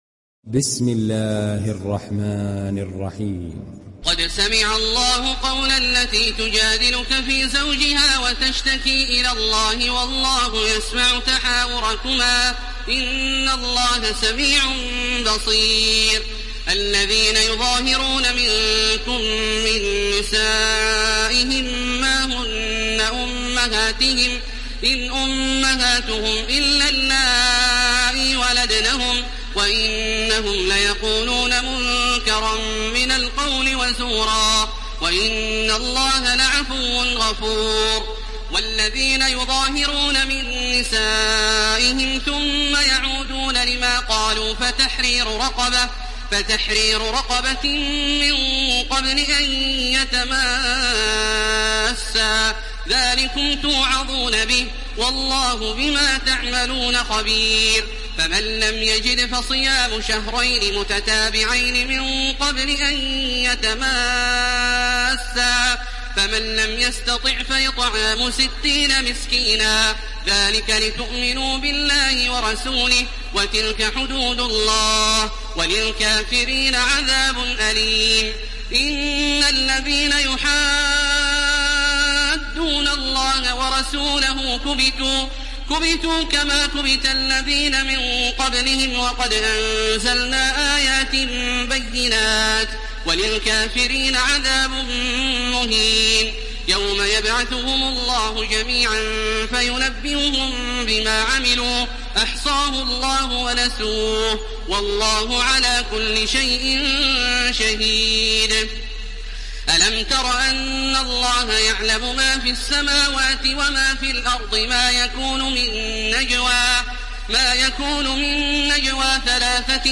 Download Surat Al Mujadila Taraweeh Makkah 1430